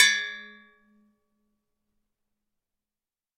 打击乐 " 宫 打击乐 08
描述：龚从各种大小的锣集合 录音室录音 Rode NT1000AKG C1000sClock音频C 009ERF边界麦克风 收割者DAW
Tag: 敲击 敲击 金属 金属 中国 命中